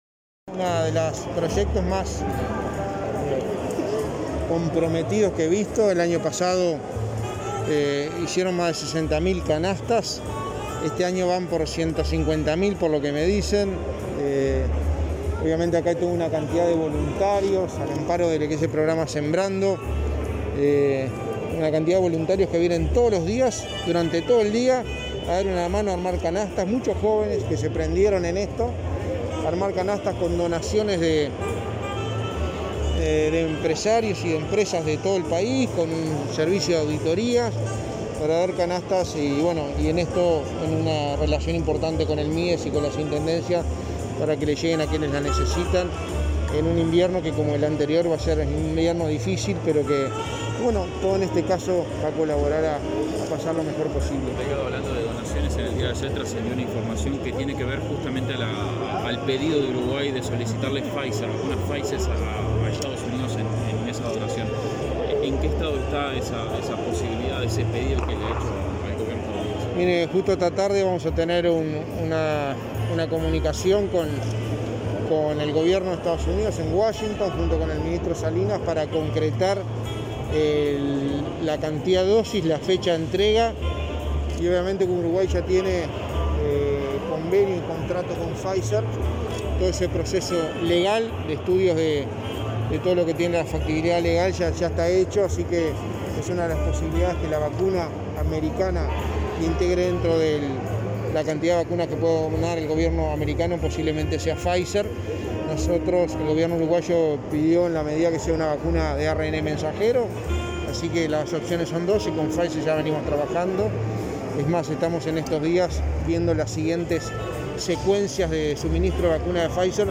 Declaraciones de prensa del secretario de Presidencia, Álvaro Delgado